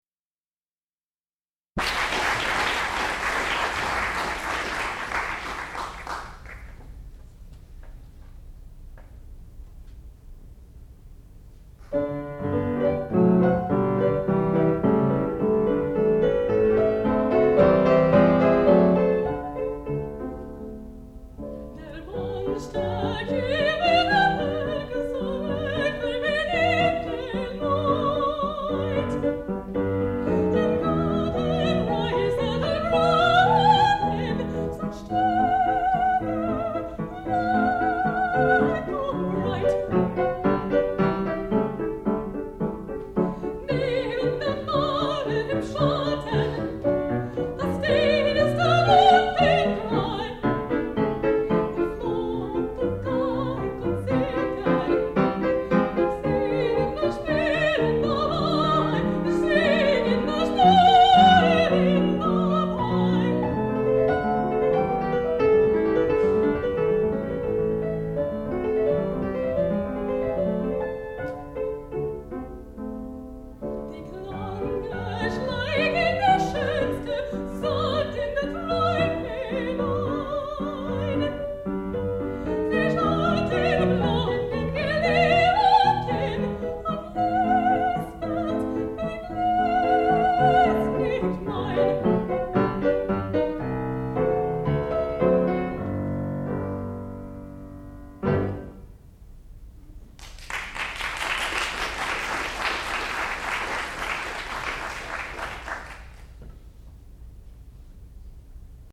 sound recording-musical
classical music
Qualifying Recital
soprano